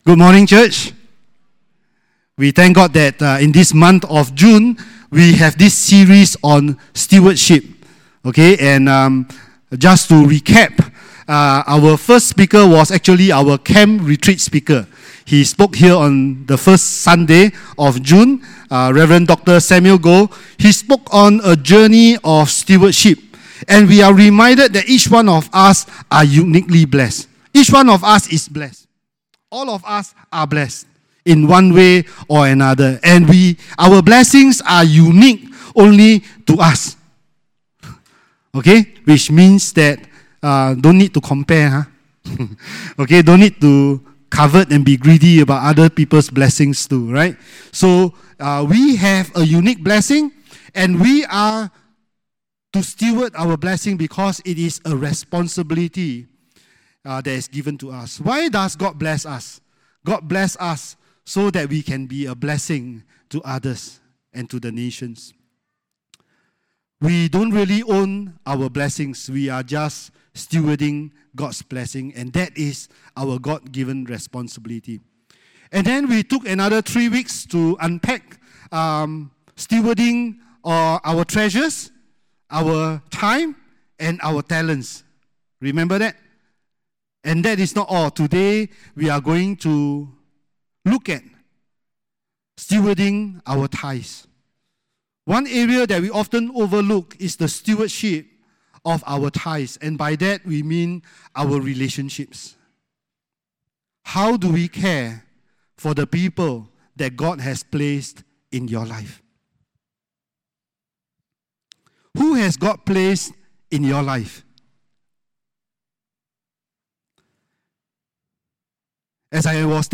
Welcome to Queenstown Baptist Church weekly sermons podcast! Join us each week as we delve into the scripture.